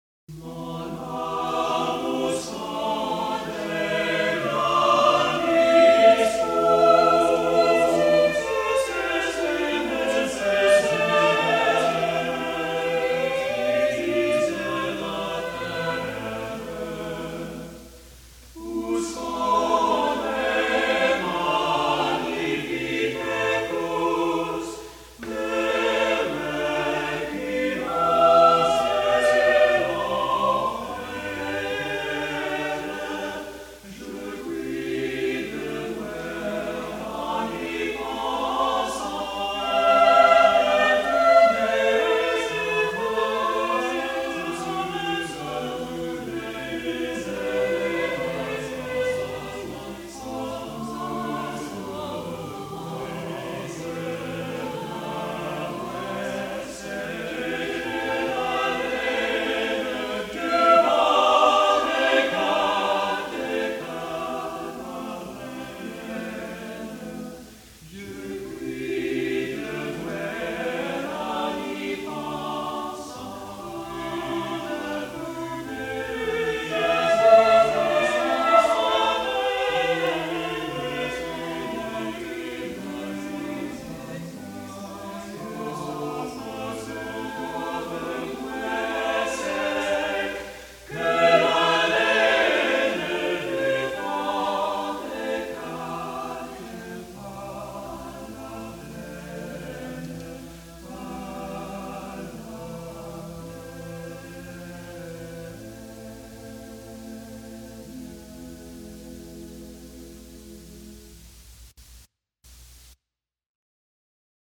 | Vocal Ensemble 'Seven Ages' 1979